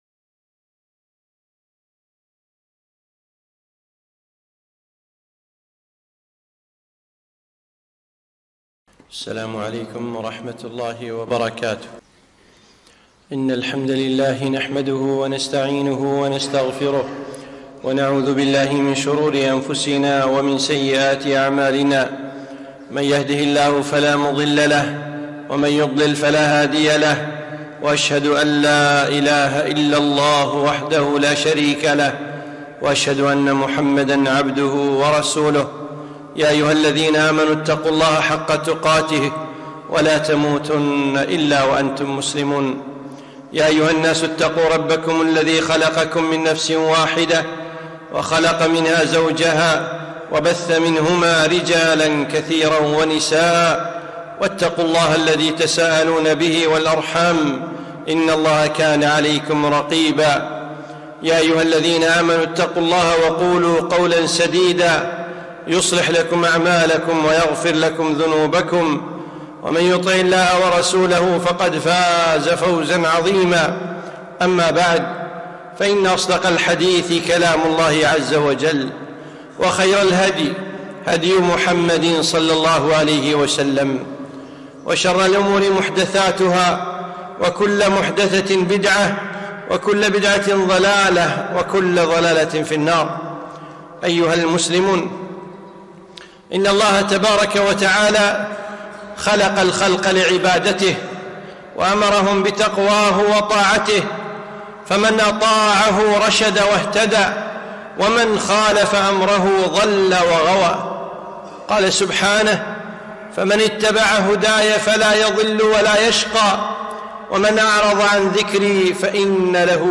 خطبة - الأمراض تنبيهات وعظات